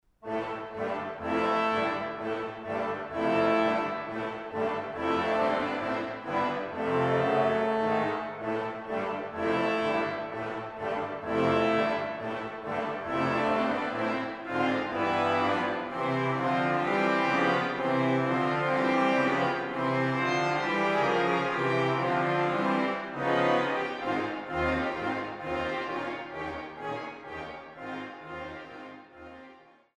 Oberlinger Orgel der Stadtkirche Dillenburg
Zwölf Orgelstücke